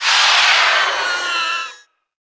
naga_mage_die.wav